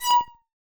glitch-in-the-system / assets / sound / Magic2.wav
Magic2.wav